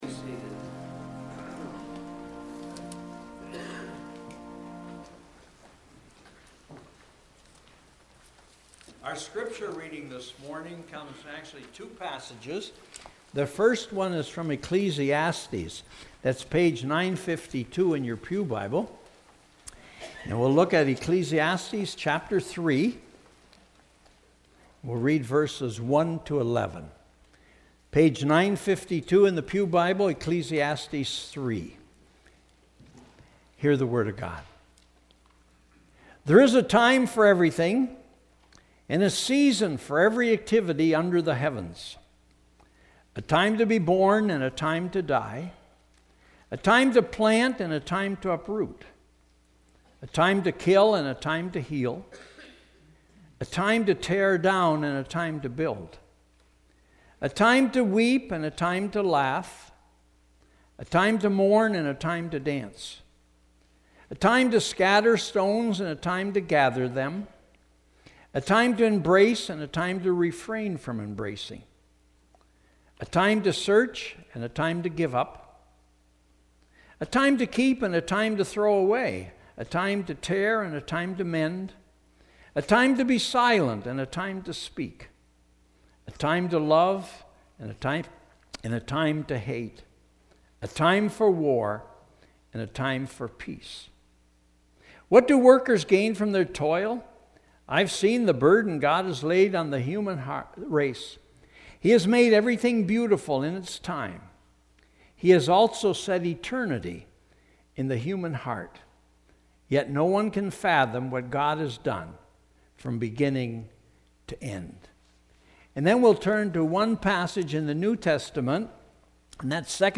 Sermons | Eighth Reformed Church